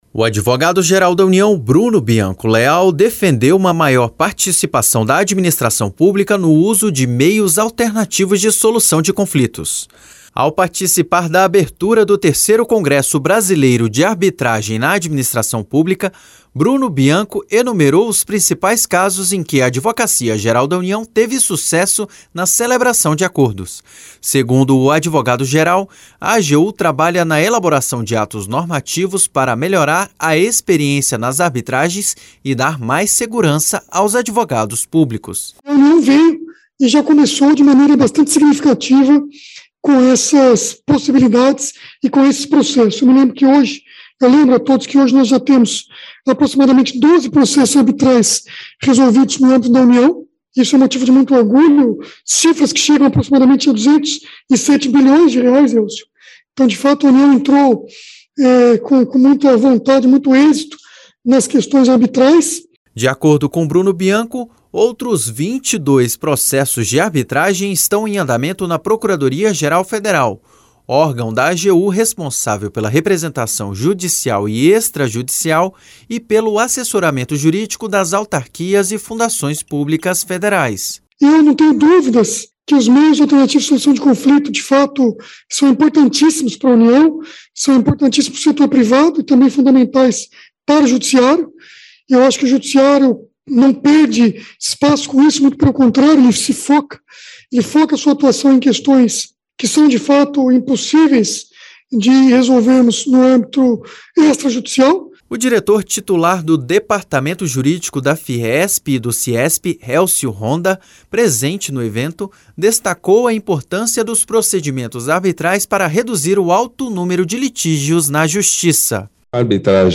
Bruno Bianco Leal discursou durante abertura do III Congresso Brasileiro de Arbitragem na Administração Pública